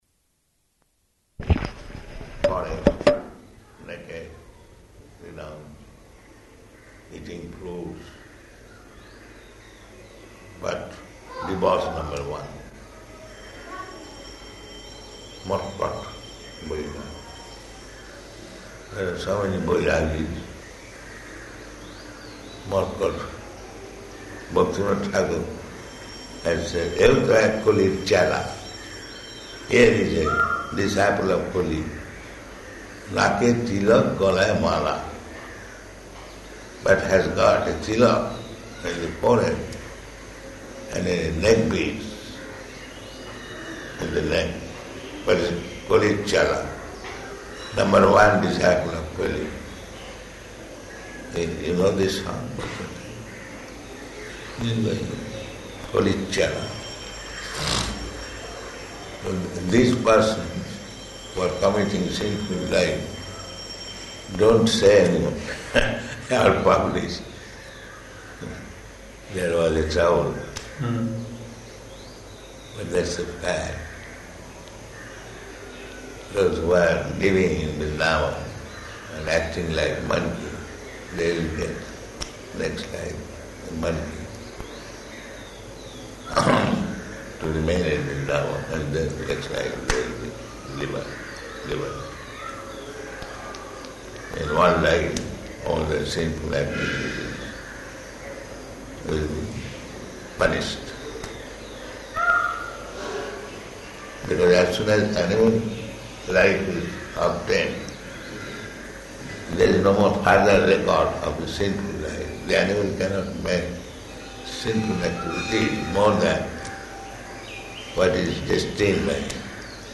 Room Conversation
Room Conversation --:-- --:-- Type: Conversation Dated: September 7th 1976 Location: Vṛndāvana Audio file: 760907R1.VRN.mp3 Prabhupāda: ...forest, naked, renounced, eating fruits, but debauch number one.